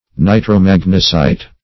Search Result for " nitromagnesite" : The Collaborative International Dictionary of English v.0.48: Nitromagnesite \Ni`tro*mag"ne*site\, n. [Nitro- + magnesite.]